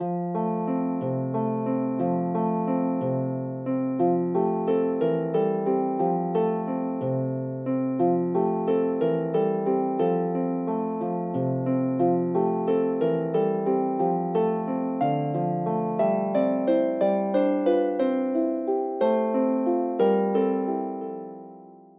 Welsh folksongs
solo lever or pedal harp